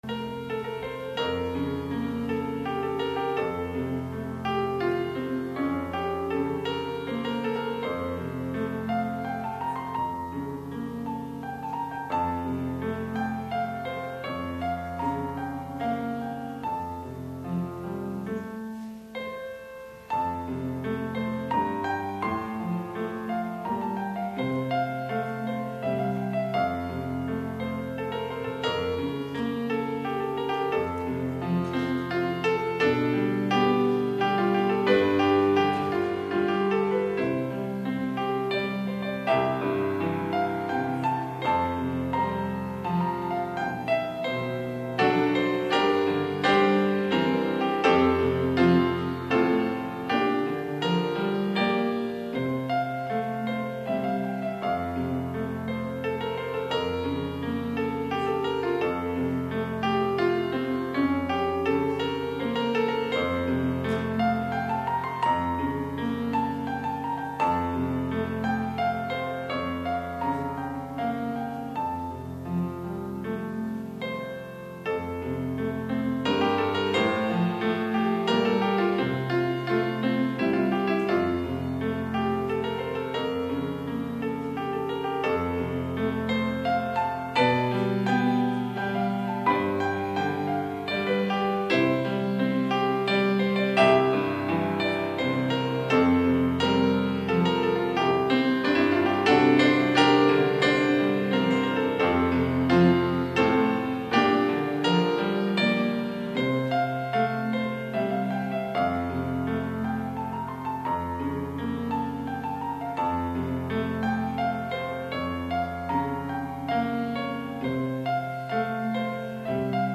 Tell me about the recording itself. Passage: Matthew 6:5-13 Service Type: Sunday AM « June 30